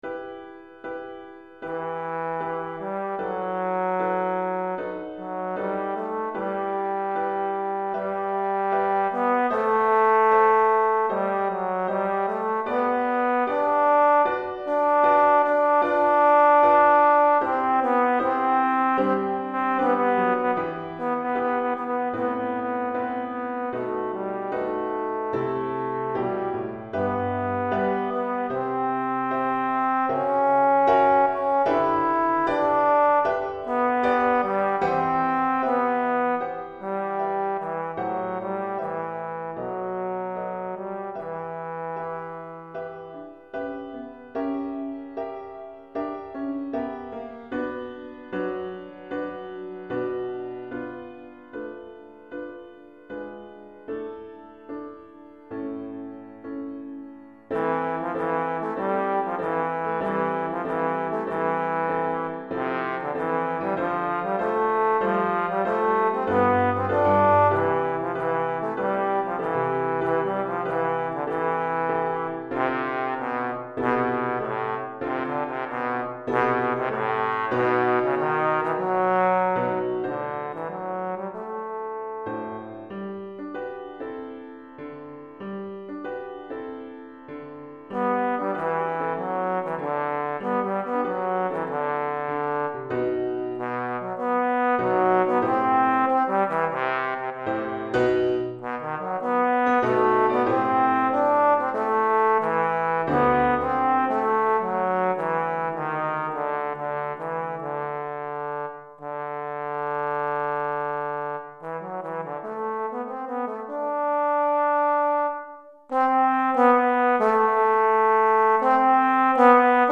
Pour trombone et piano DEGRE CYCLE 1 Durée